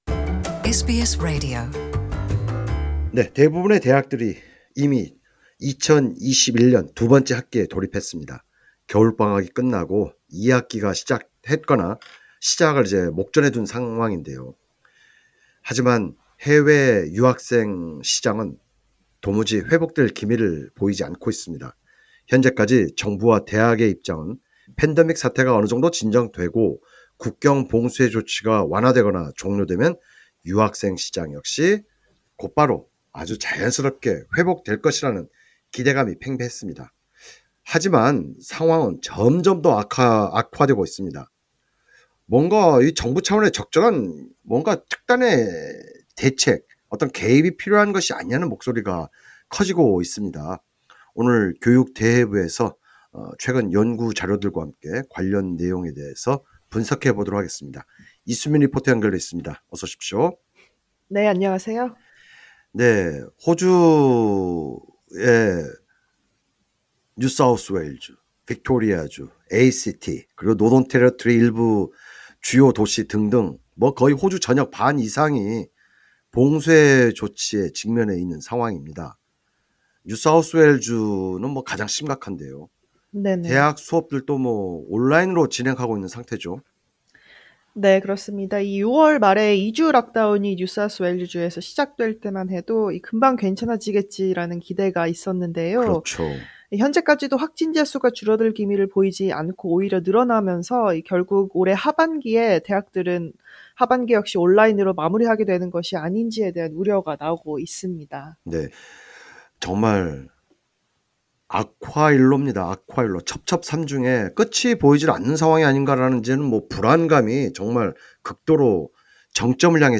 리포터와 함께 분석합니다.